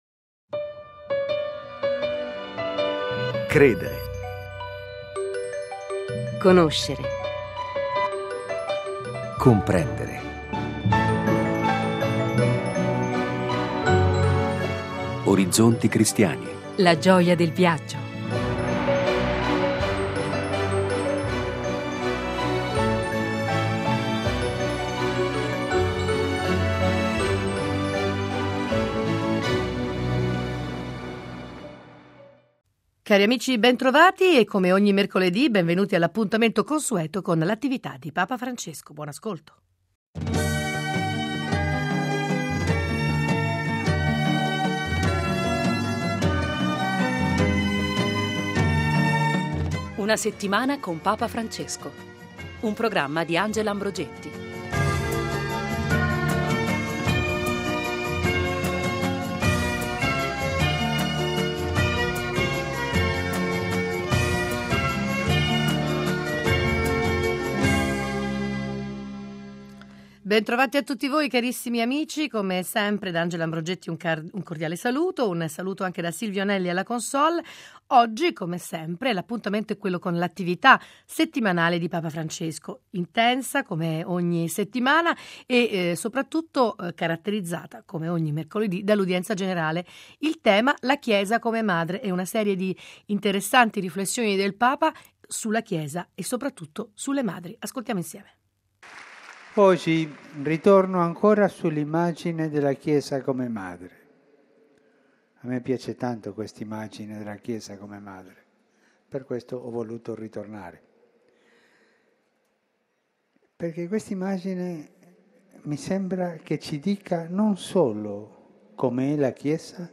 mercoledì 18 settembre Venticinque minuti ogni settimana per riascoltare i discorsi, rivivere gli incontri, raccontare le visite di Papa Francesco. Qualche ospite e la lettura dei giornali, ma soprattutto la voce del Papa a partire dall’appuntamento del mercoledì per l’Udienza Generale.